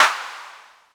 • Roomy Hand Clap Sample F Key 11.wav
Royality free hand clap sound - kick tuned to the F note. Loudest frequency: 2038Hz
roomy-hand-clap-sample-f-key-11-DNb.wav